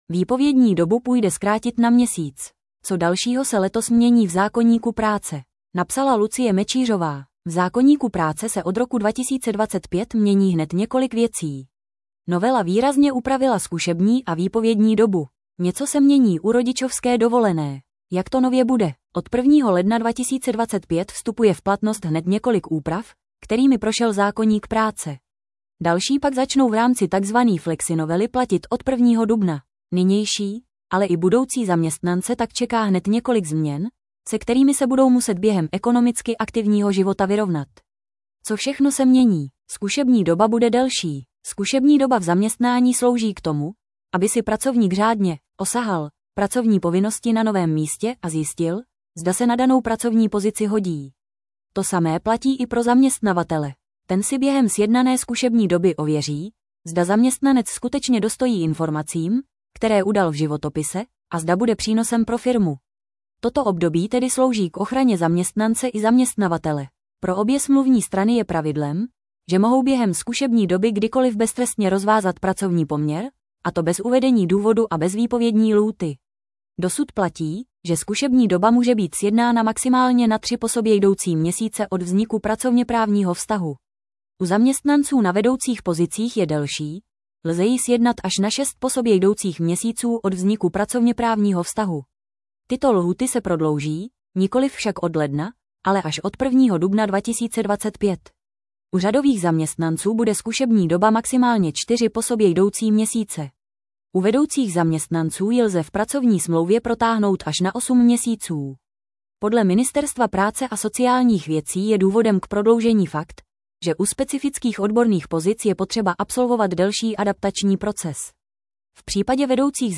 Rychlost přehrávání 0,5 0,75 normální 1,25 1,5 Poslechněte si článek v audio verzi 00:00 / 00:00 Tento článek pro vás načetl robotický hlas.